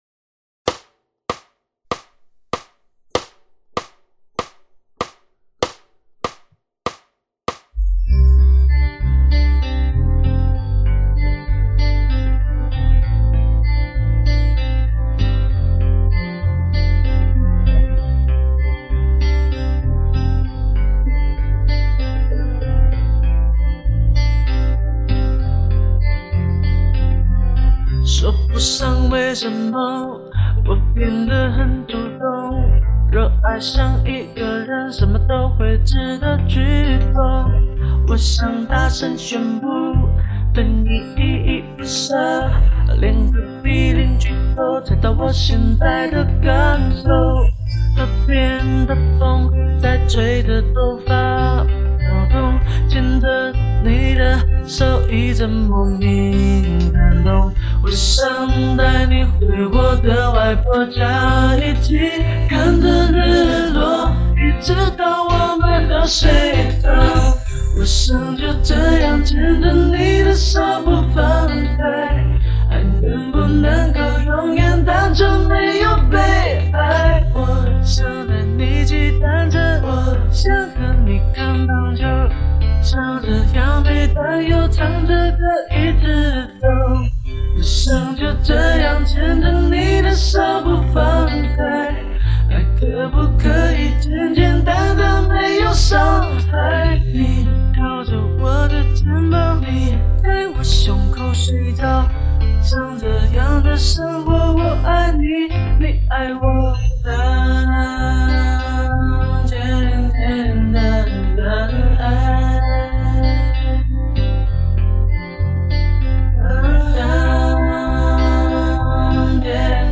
节拍：4/4，每分钟97拍